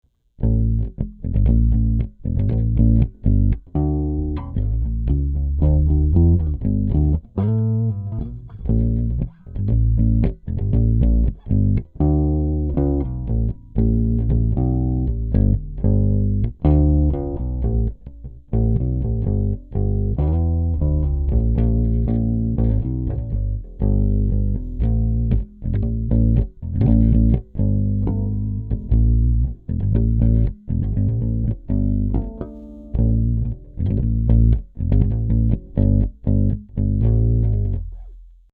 1996 SUNBURST Epiphone Rivoli Rissue VC EB-0 Style Bass with Upgraded DiMarzio Model One Humbucker PU
Now, the bass is a quality sounding professional instrument, with incredible tone, and playability.
The bass was recorded direct through a TAB-Funkenwerk V71 Tube DI (also available), with no EQ, compression, or effects: